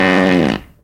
文件夹里的屁 " 屁 06
描述：从freesound上下载CC0，切片，重采样到44khZ，16位，单声道，文件中没有大块信息。准备使用！在1个文件夹中有47个屁;）
Tag: 喜剧 放屁 效果 SFX soundfx 声音